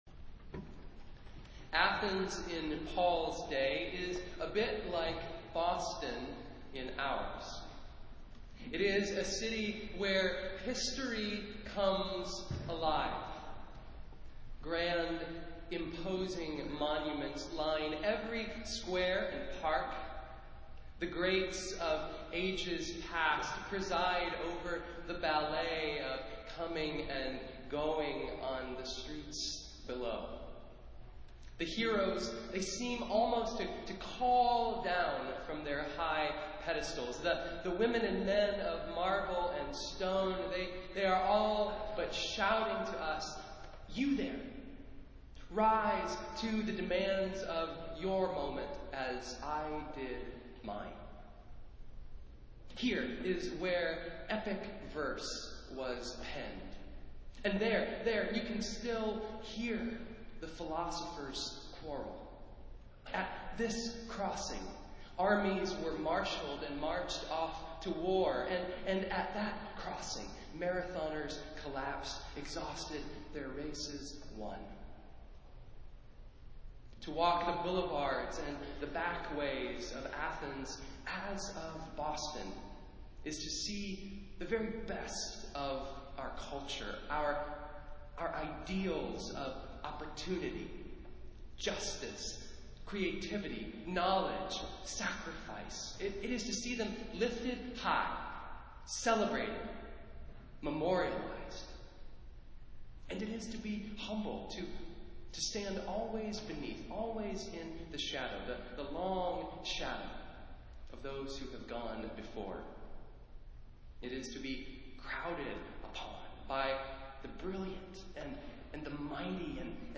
Festival Worship - Sixth Sunday after Easter